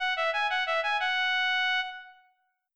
levelup.wav